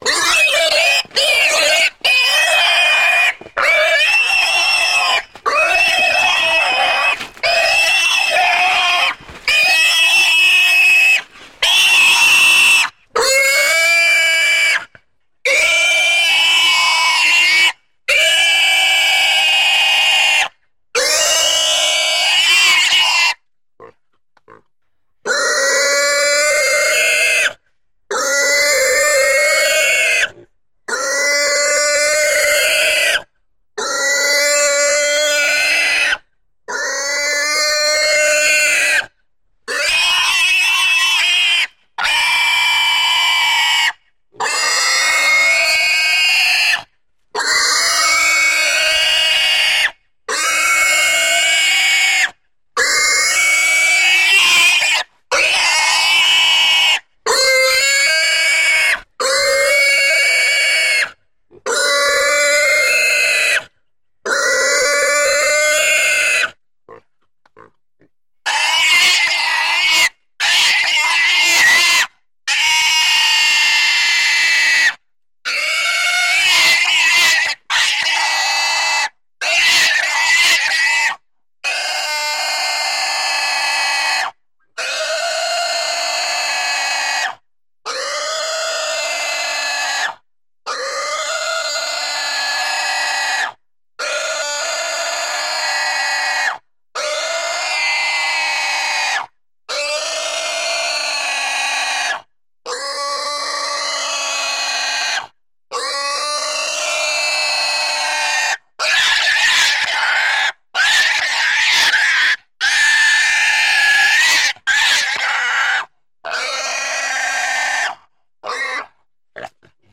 pig-sounds